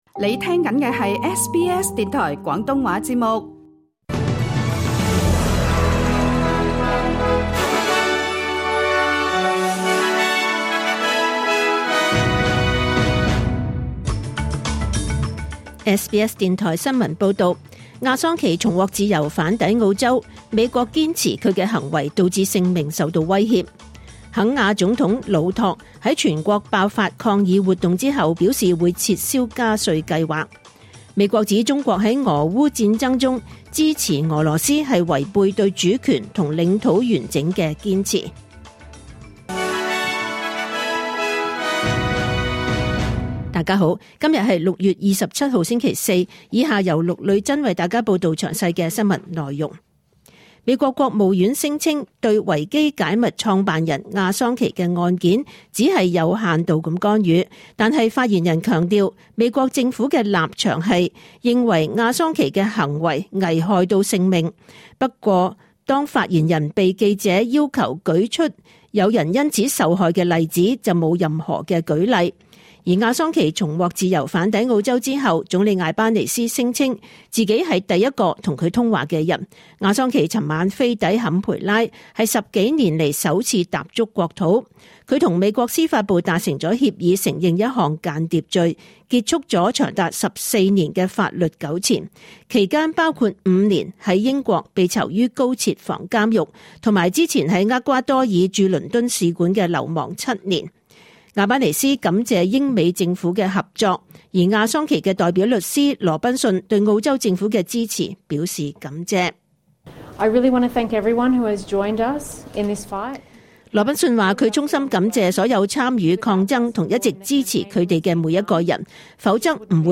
2024 年 6 月27 日 SBS 廣東話節目詳盡早晨新聞報道。